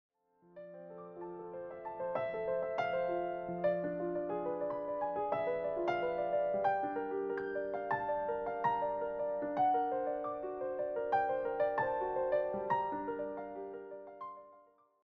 warm and familiar piano arrangements
solo piano